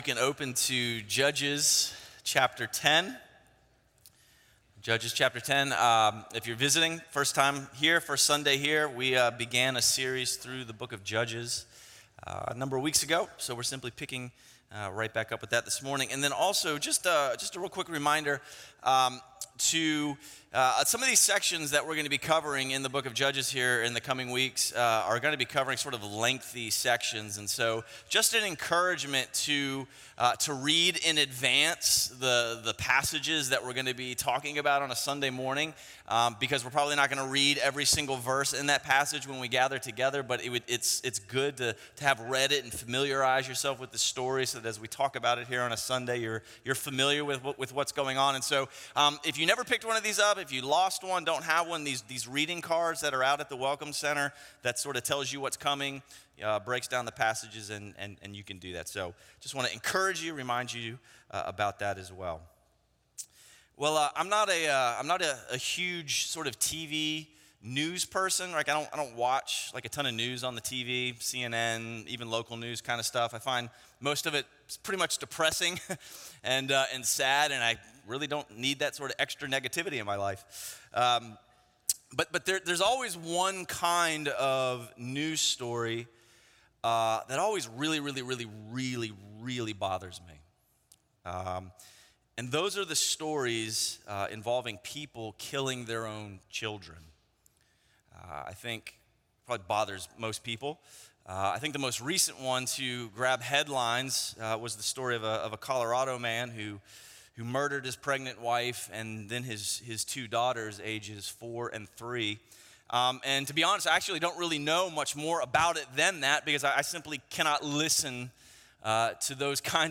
A message from the series "Life Under the Sun."